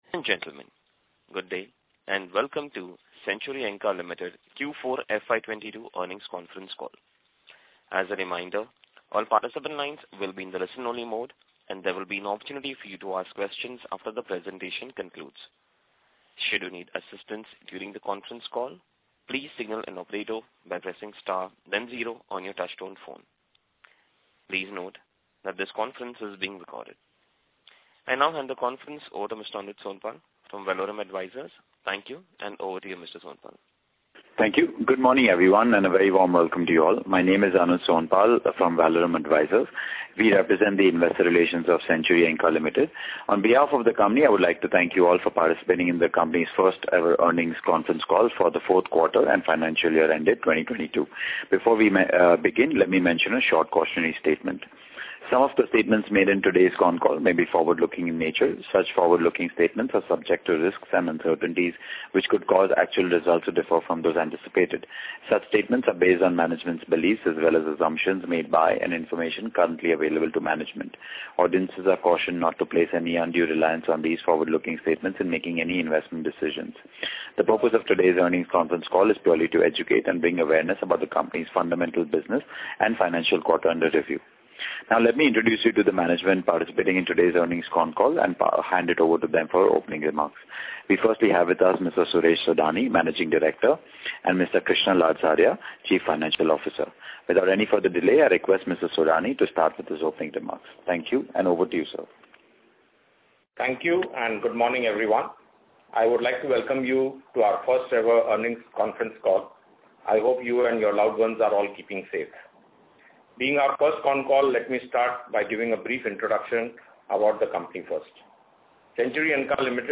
Investor Presentation & Earnings/Quarterly Calls